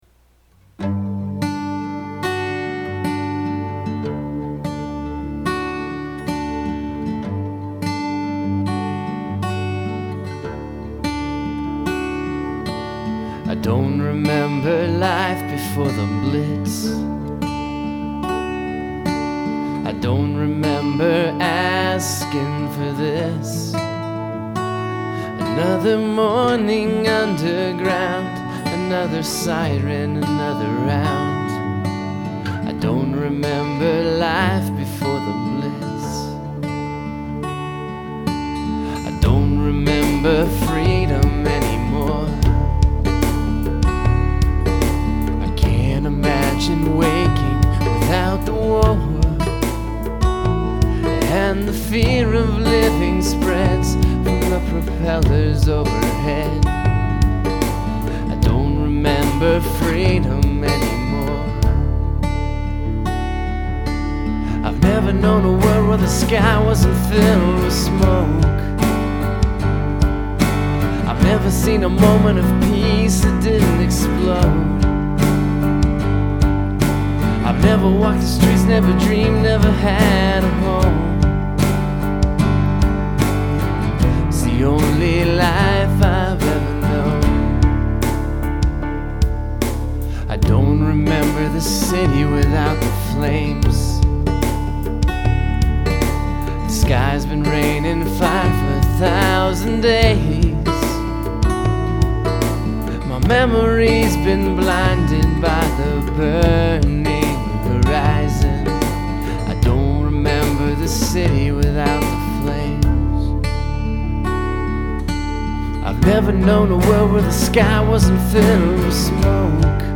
Use of choral voices
Great voice. melancholy floatation device.